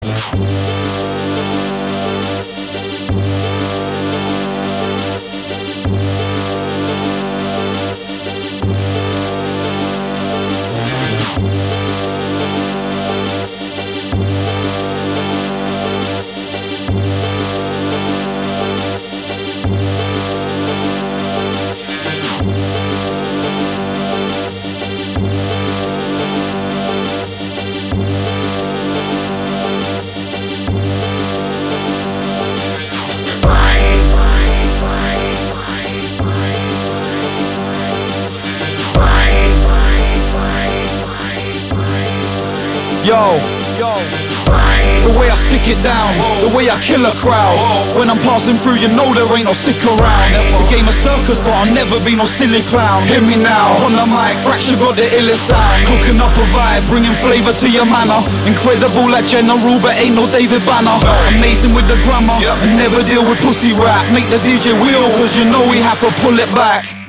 • Качество: 320, Stereo
громкие
electro
речитатив
очень громкий и надежный звонок